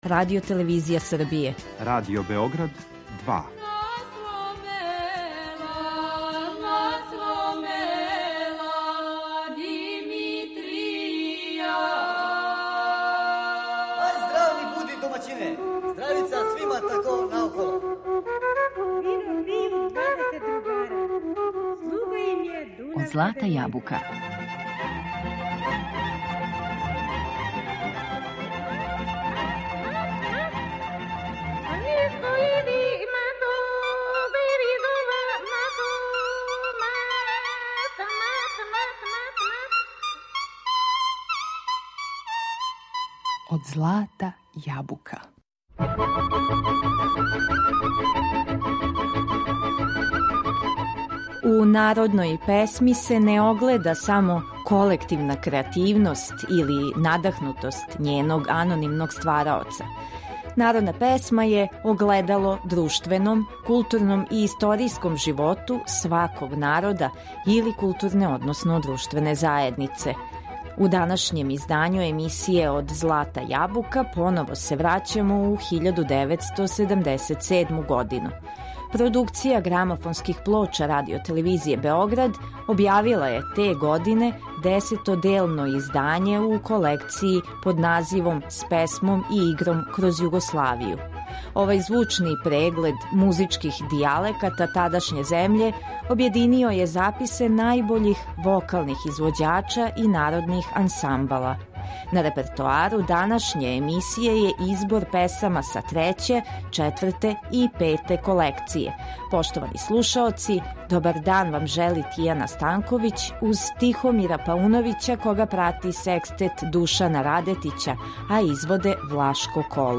Овај звучни преглед музичких дијалеката тадашње земље објединио је записе најбољих вокалних извођача и народних ансамбала. На репертоару данашње емисије је избор песама из треће, четврте и пете колекције.